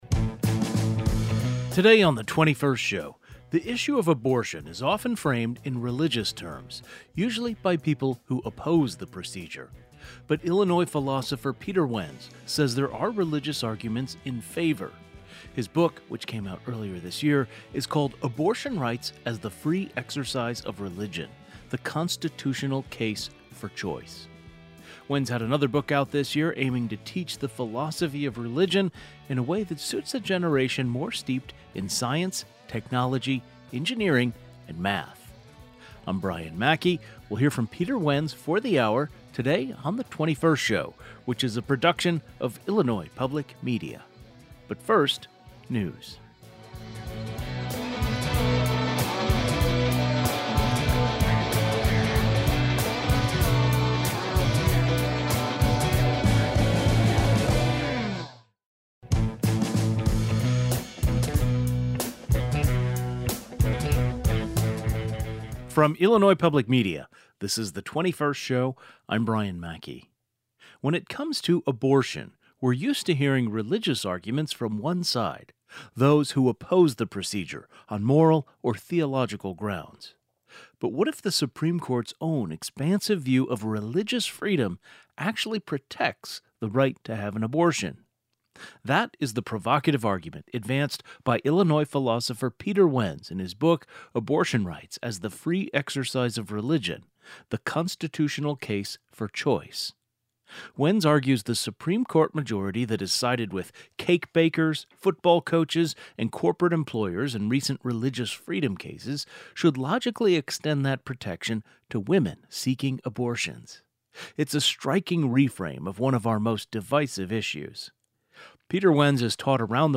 Today's show included a rebroadcast of the following "best of" segment, first aired July 1, 2025: Philosopher’s new book provides interesting nuance in abortion rights and religion discussion.